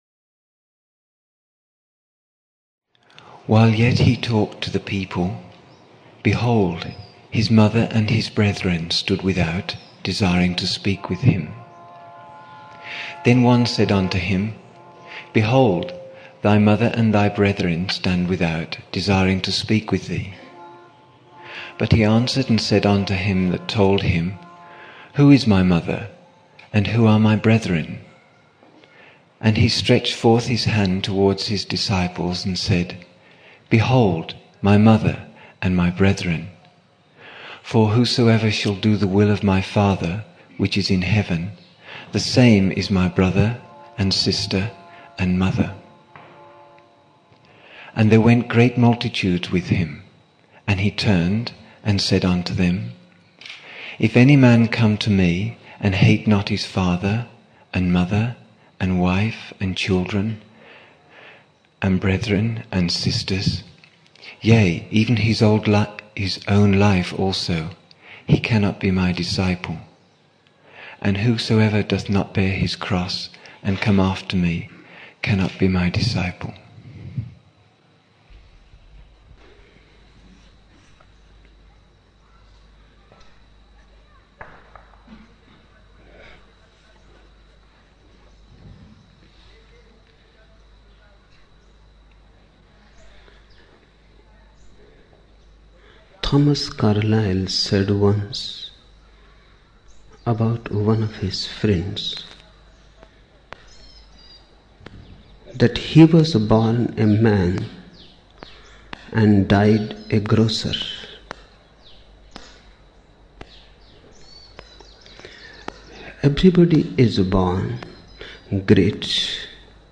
4 November 1975 morning in Buddha Hall, Poona, India